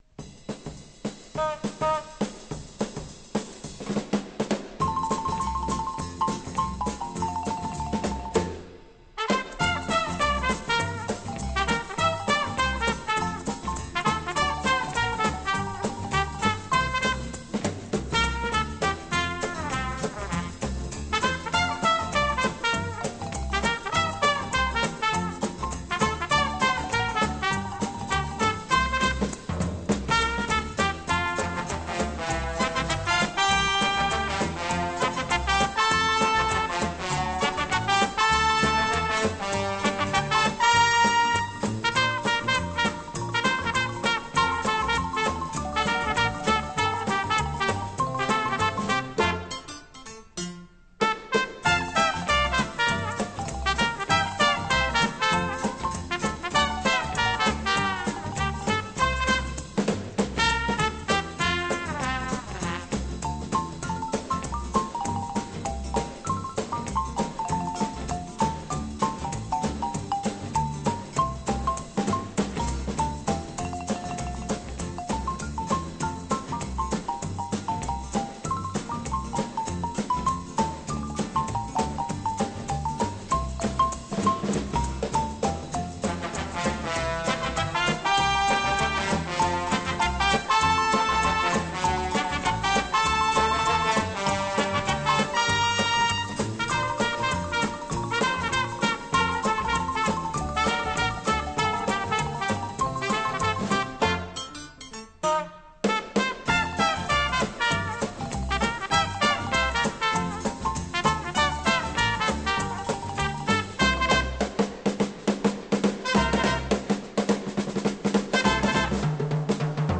Mexican Brass Style